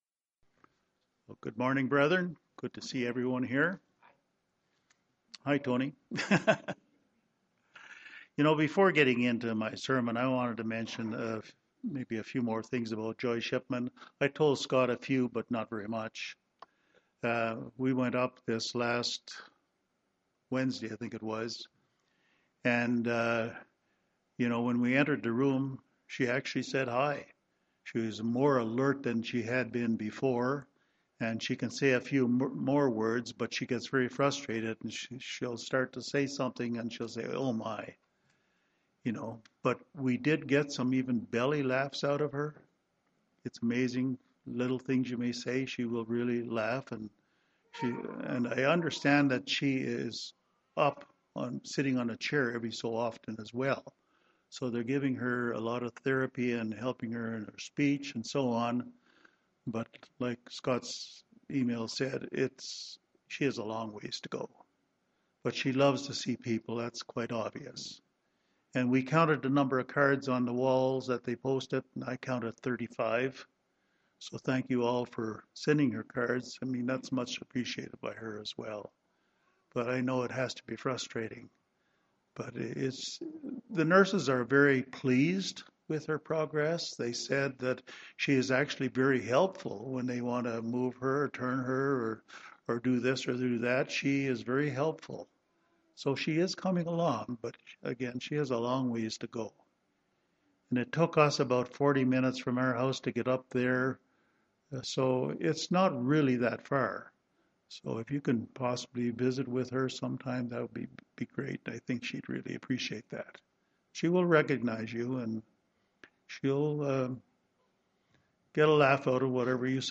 Given in Denver, CO Colorado Springs, CO